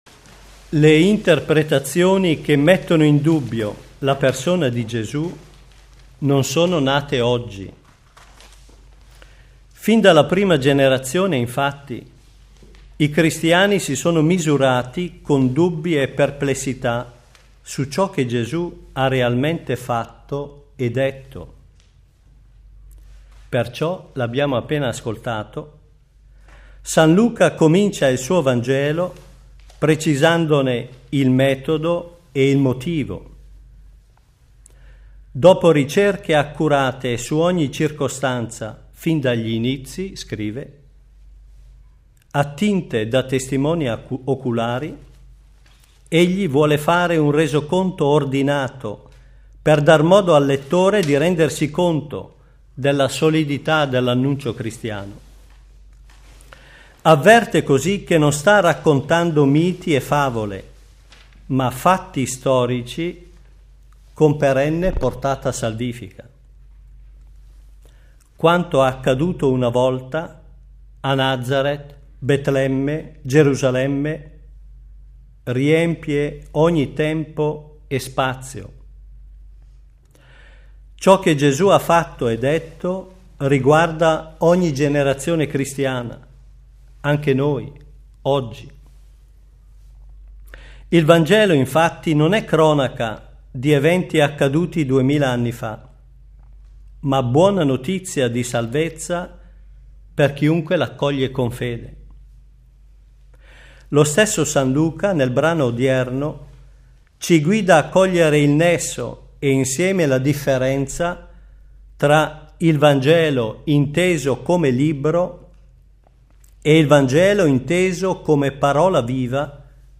Assemblea: Movimento culturale "Darsi pace"
Ogni Domenica alle 9.30 dalla Cappella Leone XIII all'interno dei Giardini Vaticani, viene trasmessa la Santa Messa secondo le intenzioni del Sommo Pontefice Benedetto XVI.